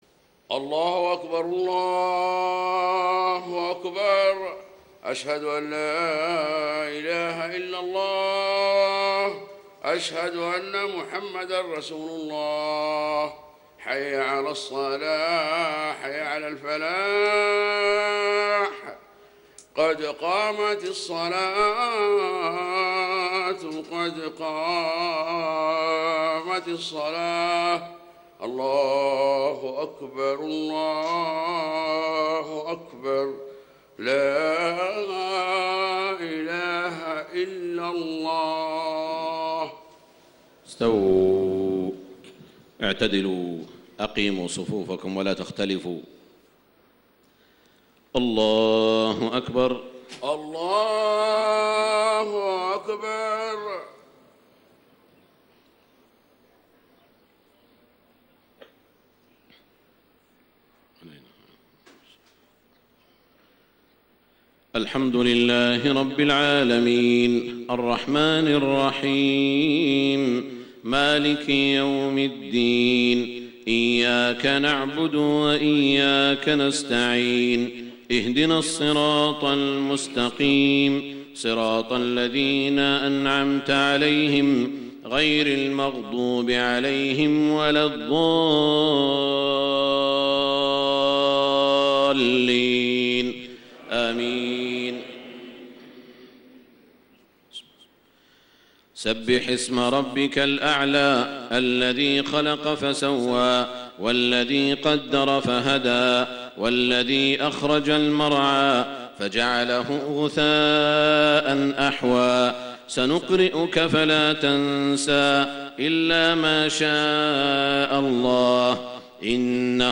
صلاة الجمعة 7-4-1435هـ سورتي الأعلى و الغاشية > 1435 🕋 > الفروض - تلاوات الحرمين